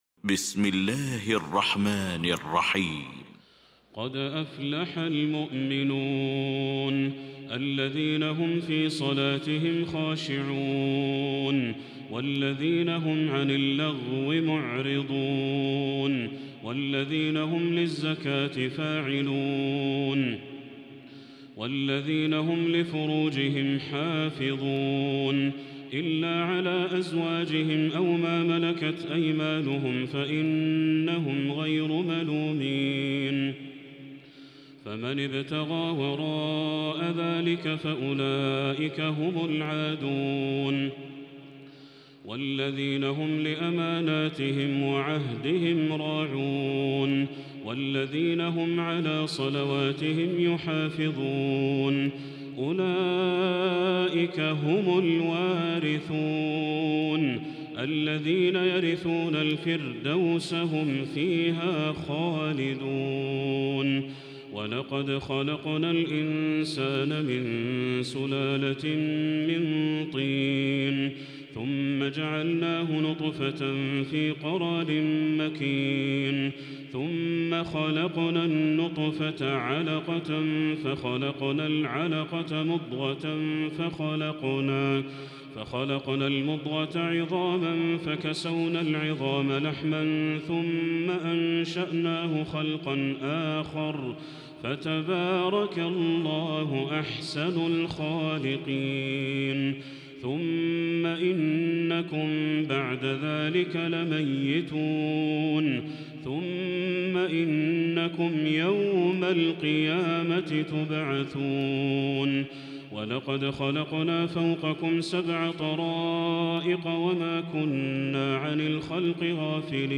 المكان: المسجد الحرام الشيخ